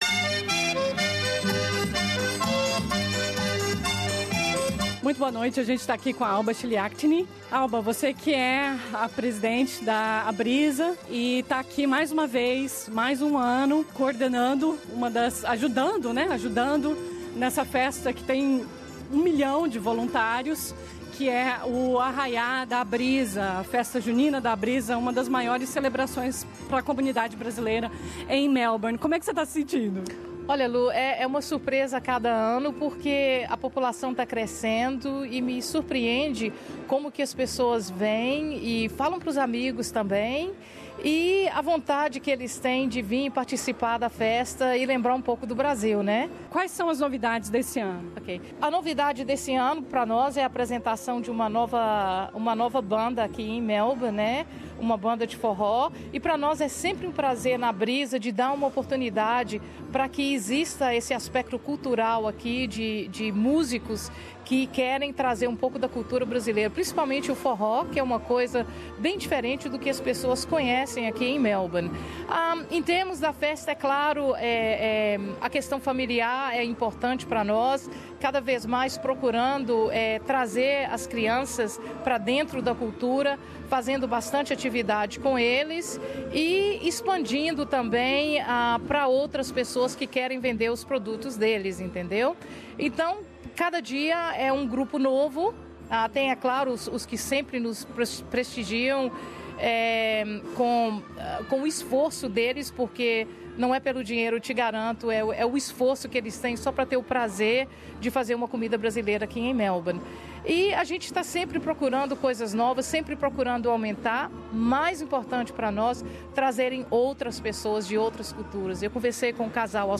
Uma das maiores celebrações da comunidade brasileira em Melbourne, a festa junina da Abrisa reuniu pessoas de todas as idades e nacionalidades e mais uma vez transformou o salão da igreja St Brigid em um grande arraial. Ouça entrevista